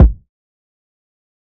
TC Kick 08.wav